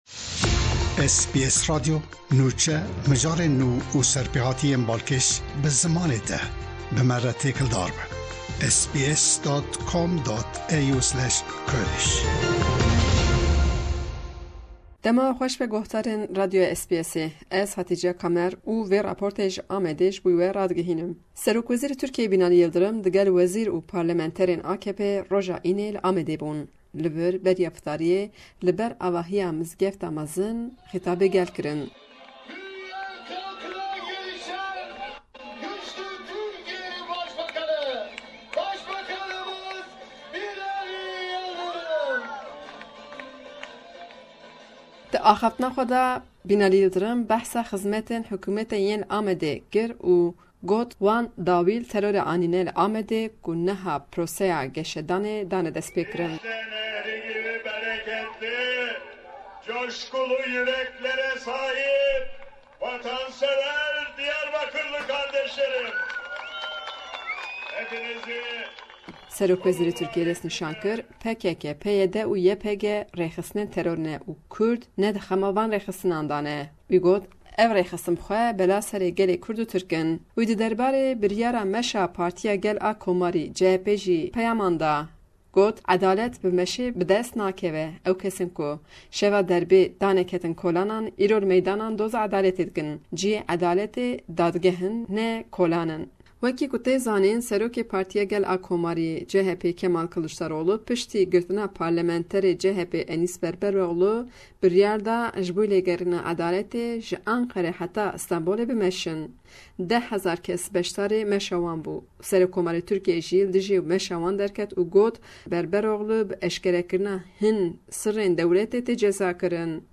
Raporta peyamnêra me